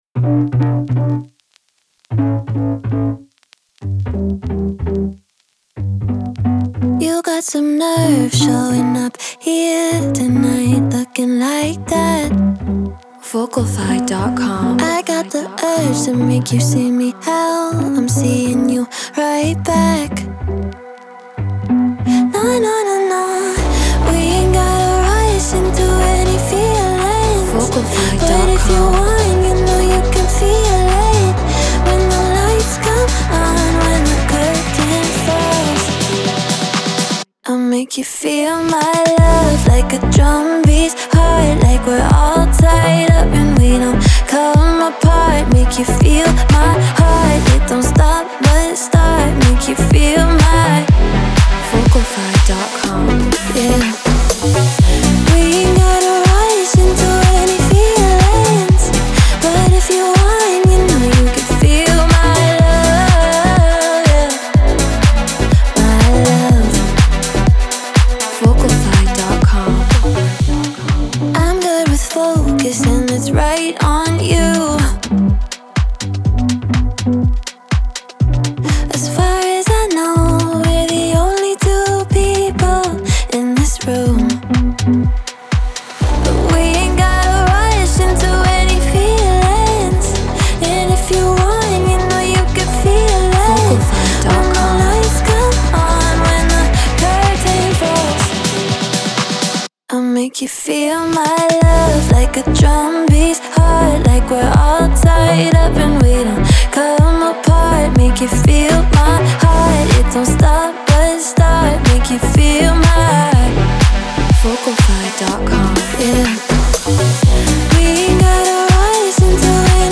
House
123 BPM
Bmin